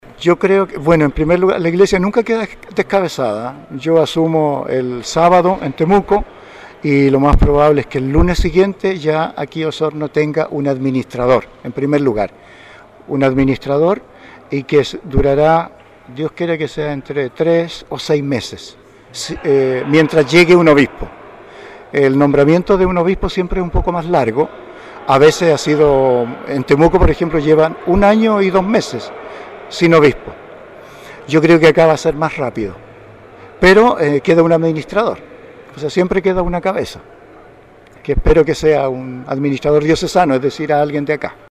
Con la Iglesia Catedral San Mateo completa de fieles, en la tarde de ayer se realizó la Misa-Acción de Gracias por su servicio como pastor de la Diócesisde Monseñor Jorge Concha Cayuqueo.